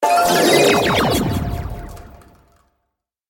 Магическое втягивание в портал с эффектом звука